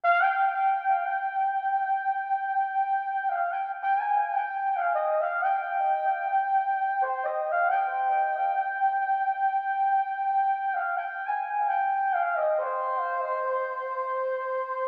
01 washy lead B1.wav